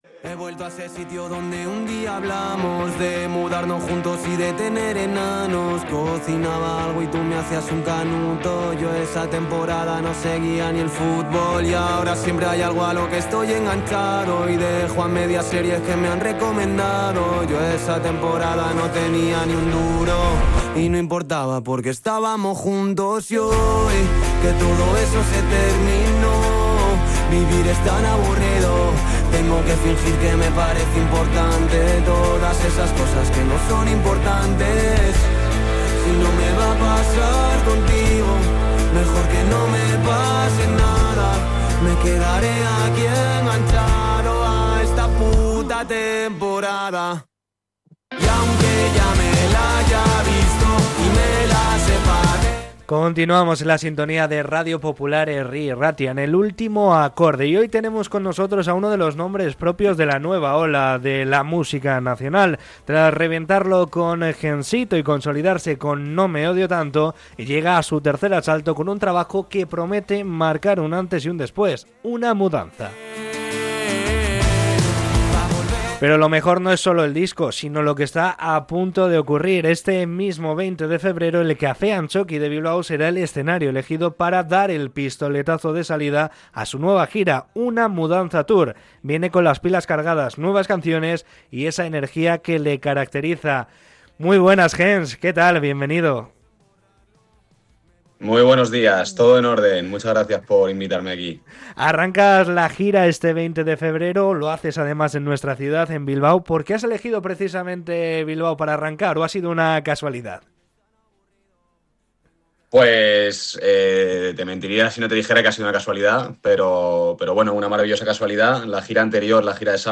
Entrevista con el cantante segoviano que visitará Bilbao la próxima semana